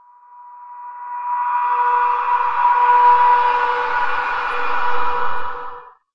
描述：由大量处理过的人声录音产生的无人机
Tag: 雄蜂 处理 语音